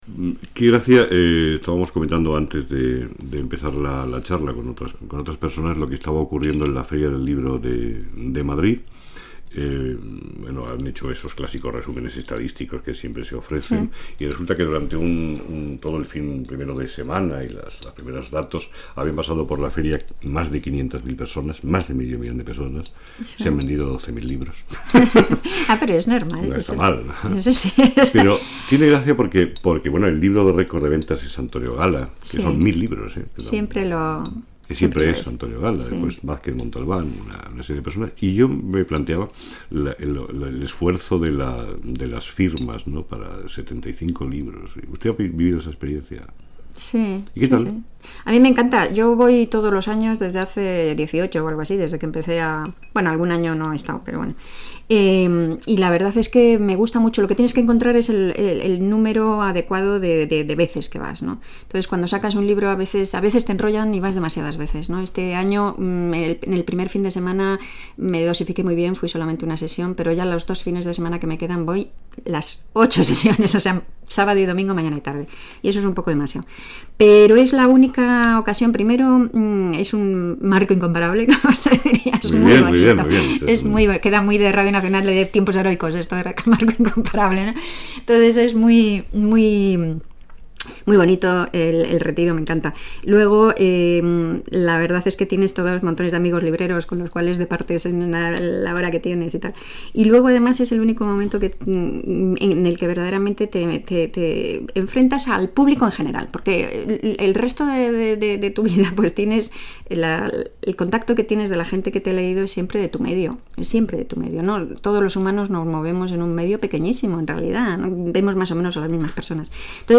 Entrevista a l'escriptora Rosa Montero sobre la Feria del Libro de Madrid